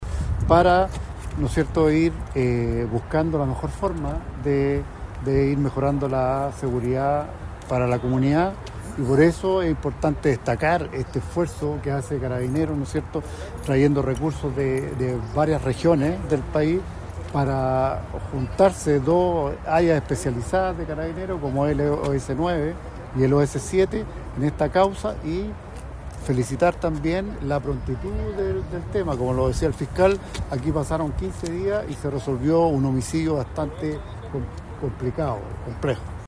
El seremi de Seguridad Pública, Richard Soto, planteó que “este resultado se enmarca en las instrucciones que nos ha dado el Presidente Kast”.